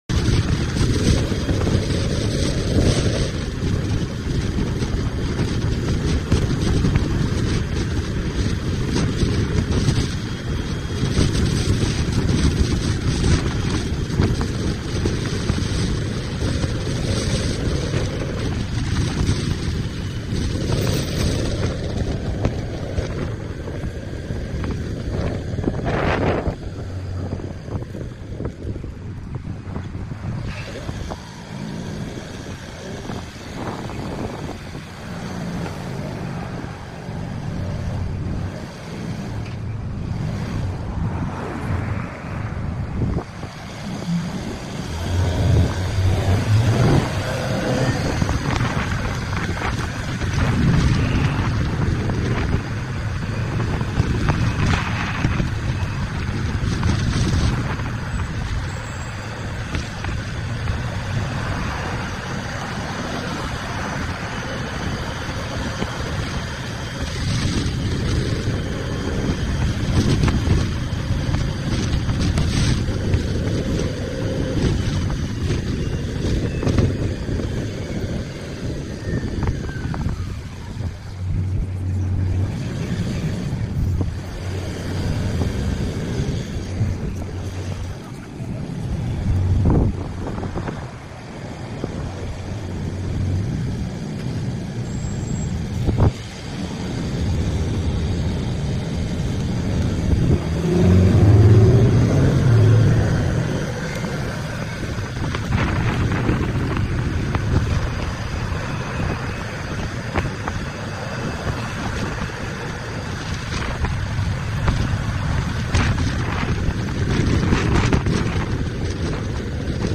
Thunder’s rolling in quietly. Driving sound effects free download